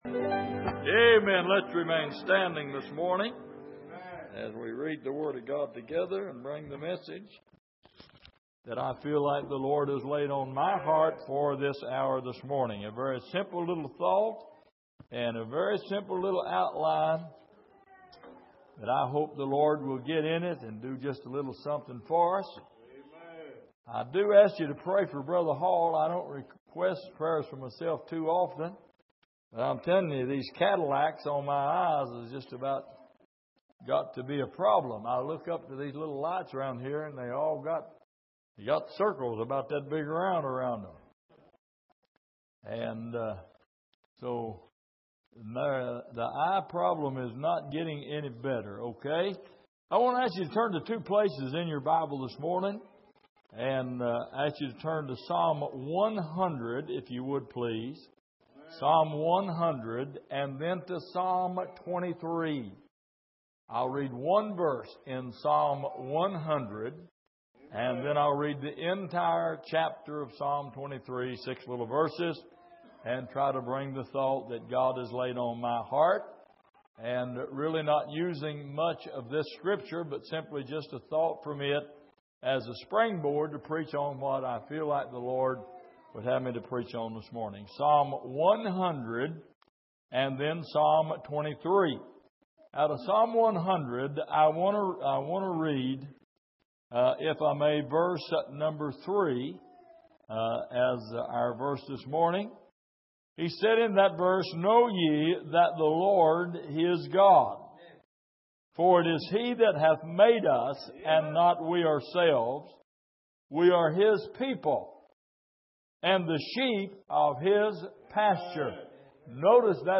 Passage: Psalm 23:4 Service: Sunday Morning